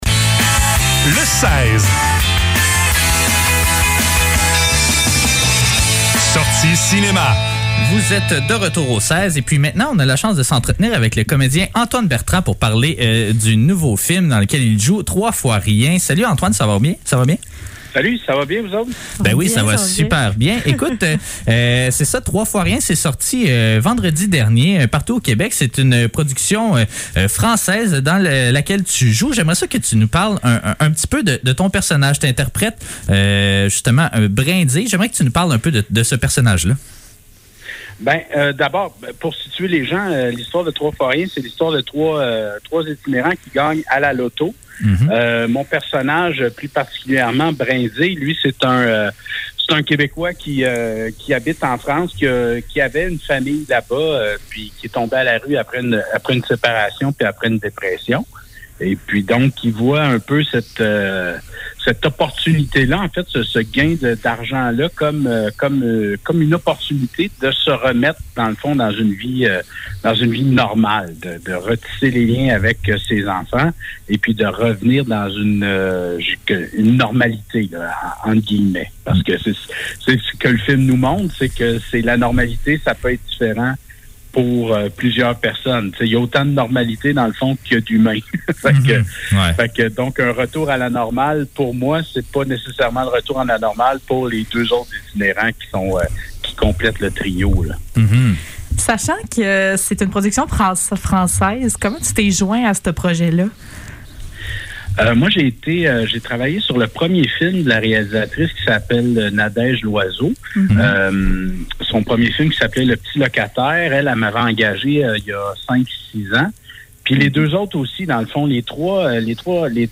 Le seize - Entrevue avec Antoine Bertrand - 23 mars 2022
Entrevue-Antoine-Bertrand.mp3